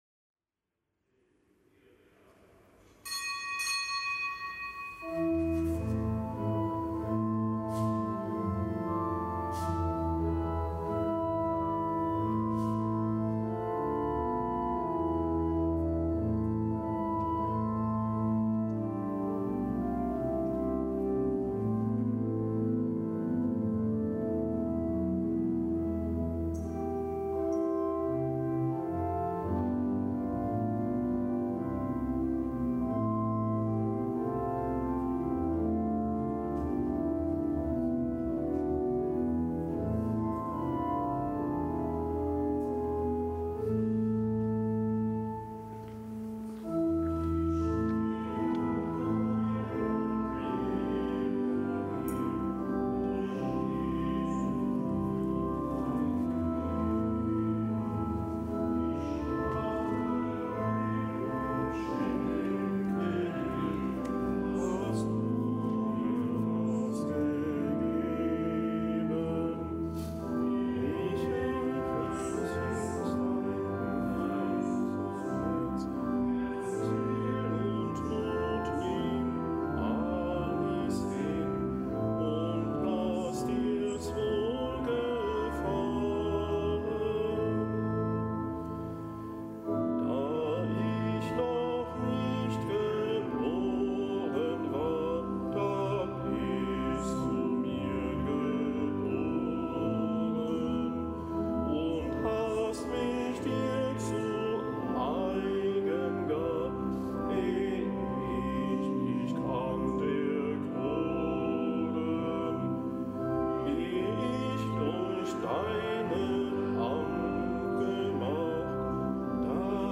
Kapitelsmesse aus dem Kölner Dom am Freitag der Weihnachtszeit. Zelebrant: Weihbischof Rolf Steinhäuser.